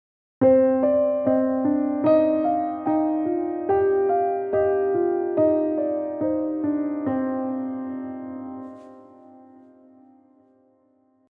Yes, just a C minor scale cut in groups of 3 notes and played by both hands with a 1-beat offset.